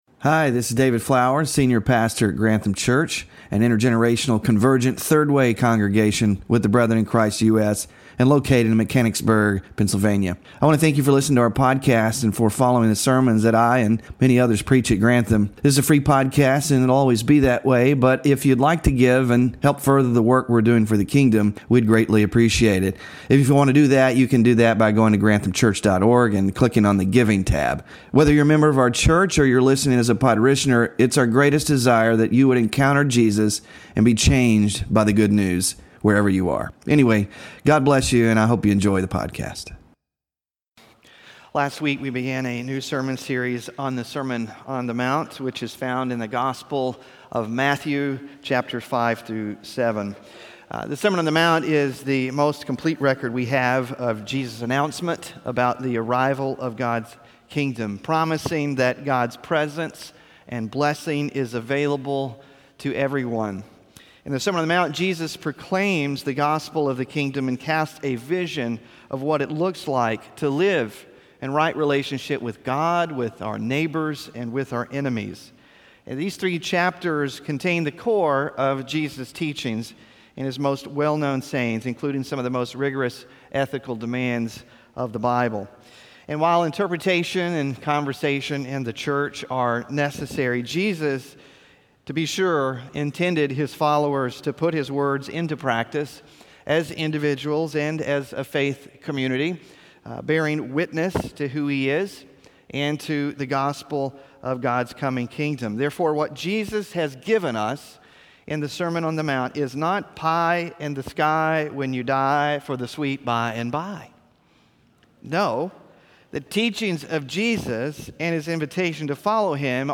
Sermon Focus: Jesus begins his sermon with an opening description of those who are being invited into the Kingdom, and the sort of people that God blesses.